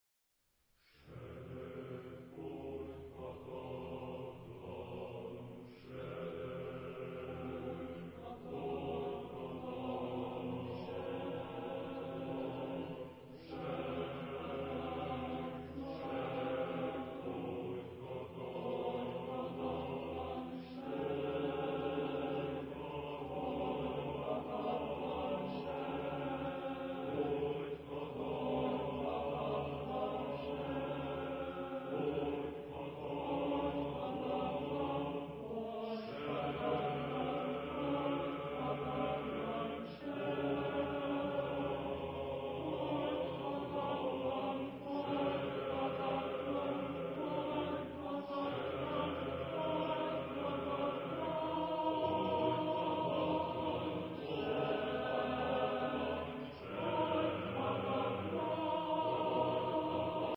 Genre-Stil-Form: zeitgenössisch ; Begräbnisgesang ; Chor
Chorgattung: SATBB  (5 gemischter Chor Stimmen )